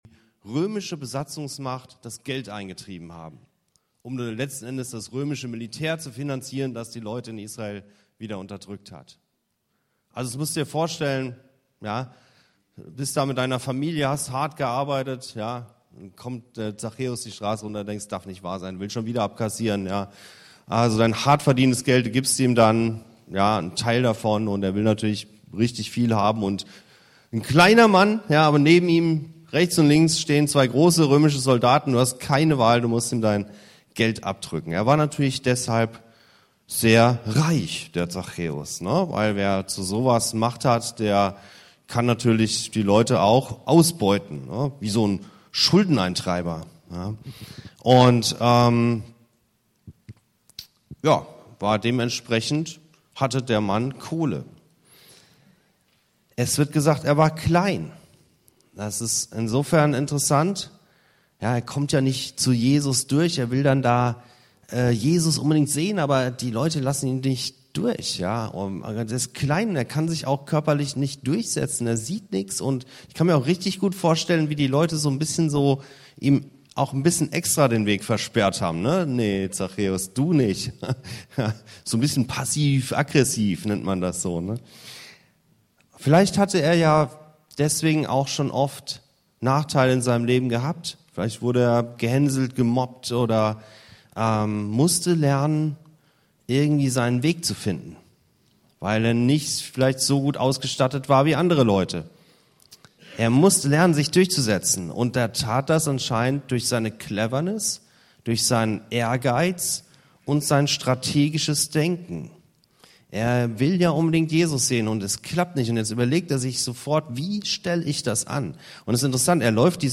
(bei dieser Predigt fehlen die ersten 3 Minuten) Beschreibung: Zachäus war reich, mächtig – und trotzdem verloren.